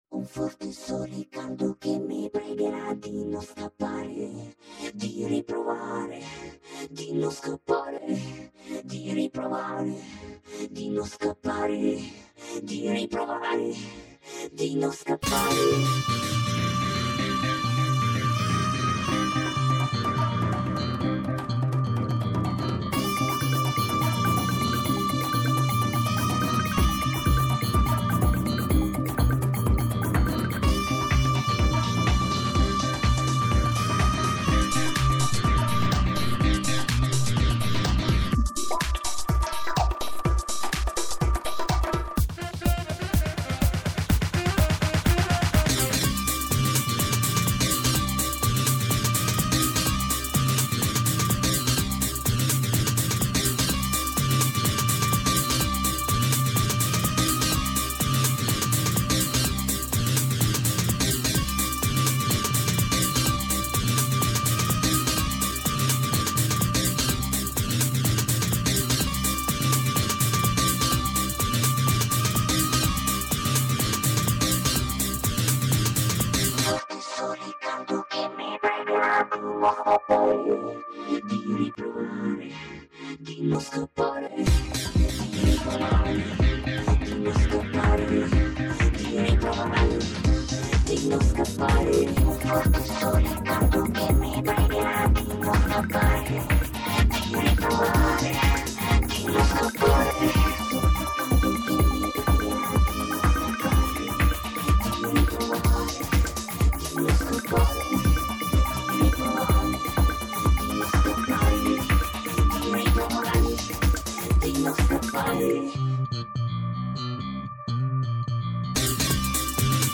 ELECTRO DANCE